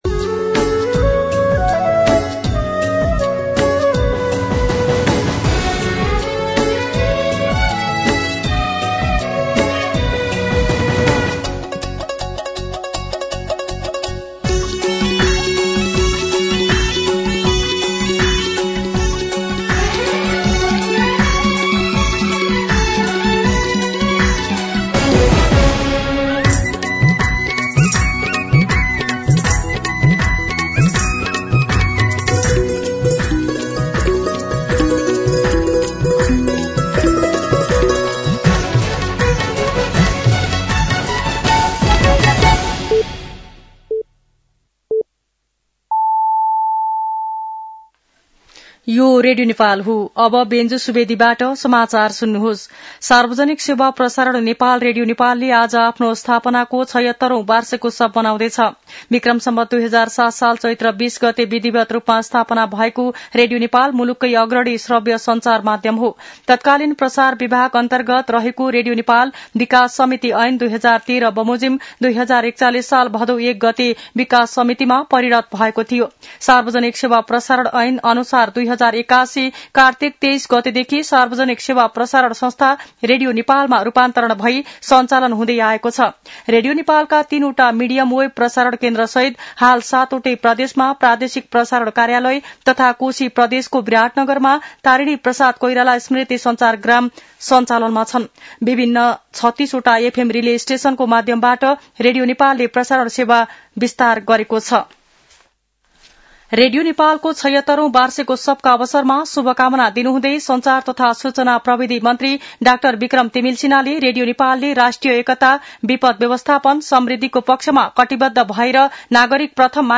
दिउँसो १ बजेको नेपाली समाचार : २० चैत , २०८२
1pm-News-20.mp3